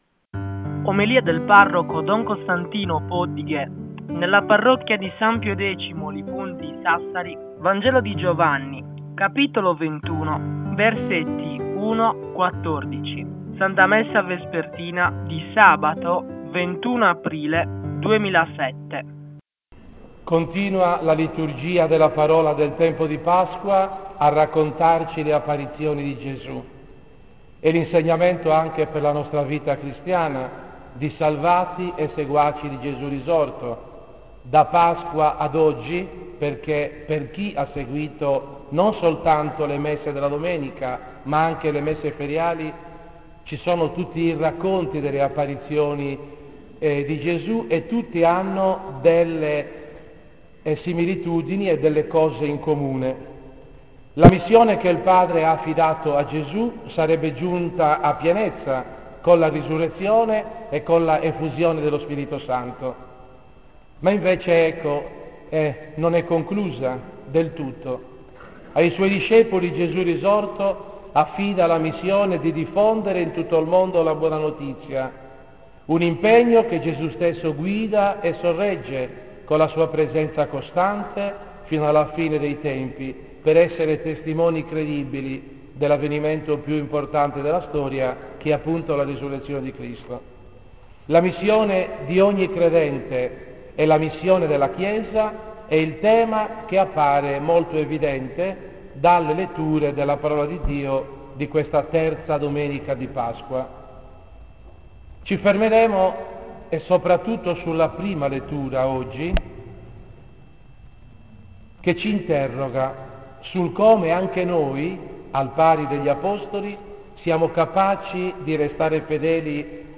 In questa sezione puoi ascoltare le omelie del parroco sul Vangelo della domenica indicata.
OMELIE DELLA PASQUA 2007